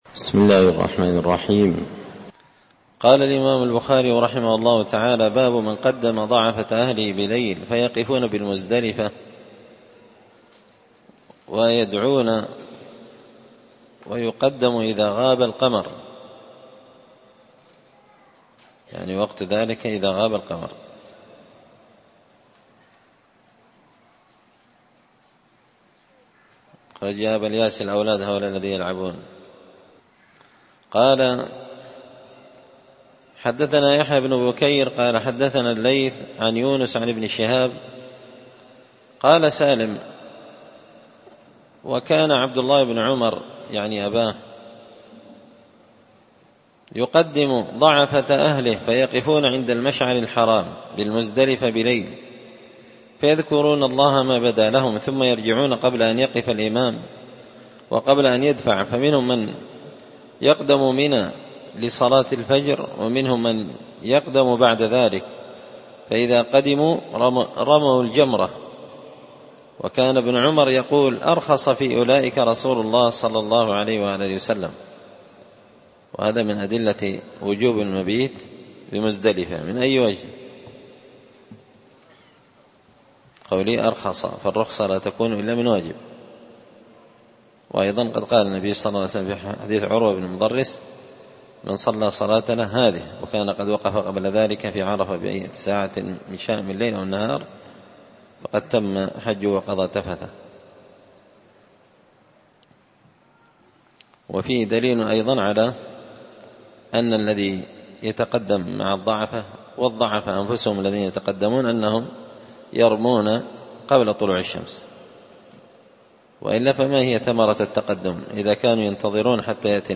كتاب الحج من شرح صحيح البخاري – الدرس 86